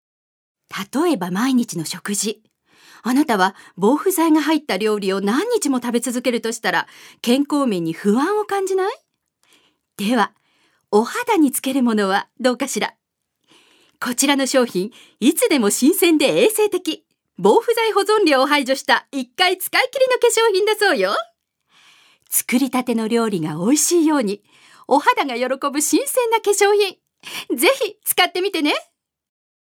女性タレント
音声サンプル
ナレーション４